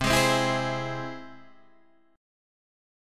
C Augmented